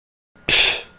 Play, download and share PSHH original sound button!!!!
pshh.mp3